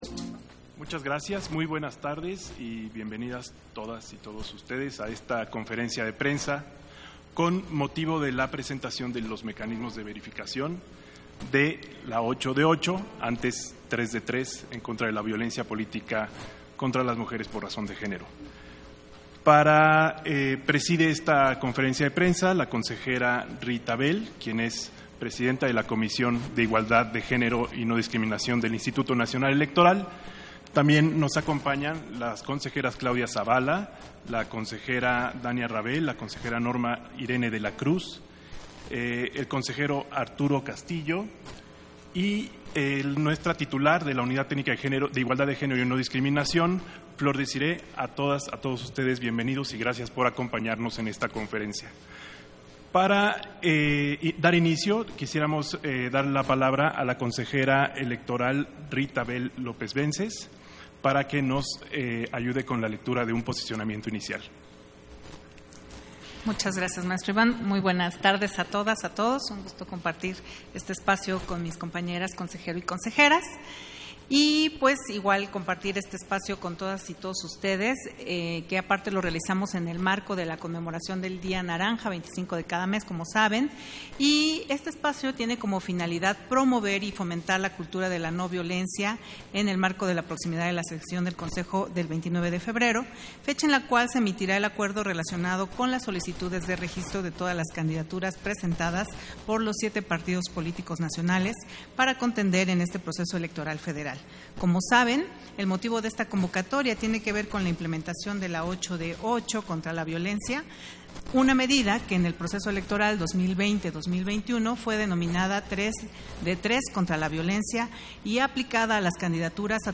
260224_AUDIO_CONFERENCIA-DE-PRENSA-1